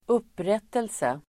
Ladda ner uttalet
upprättelse substantiv, redress Uttal: [²'up:ret:else] Böjningar: upprättelsen, upprättelser Definition: det att bli rentvådd från orättvisa anklagelser (be given redress (satisfaction) for unjust accusations)